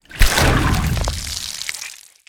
exit_blood.ogg